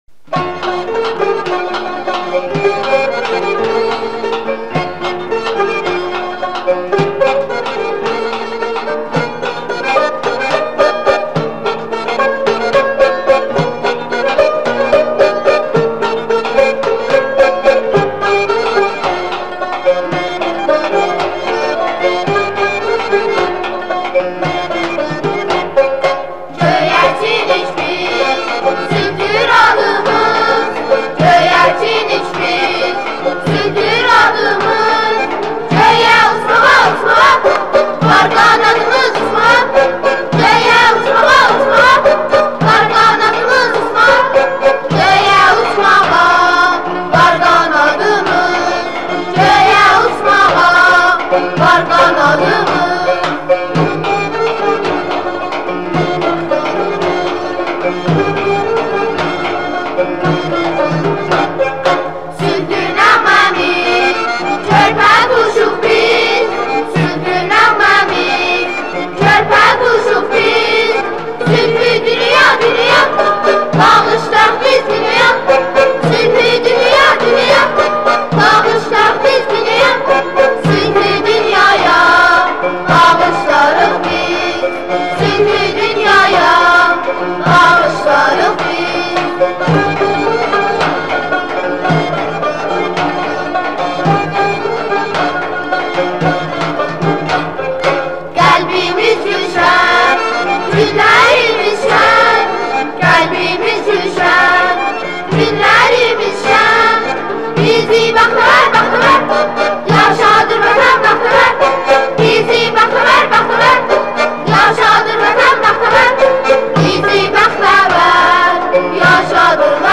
ЛУЧШИЕ БРИТАНСКИЕ СЕРИАЛЫ << за всё время >> Произношение слова Biz Слово Biz - произносится, как [биз] Песни со словом Biz Göyərçinik Biz - Uşaq Mahnısı Книга со словом Biz 1.
goyercinik-biz-usaqmahnisi.mp3